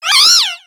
Cri de Gaulet dans Pokémon X et Y.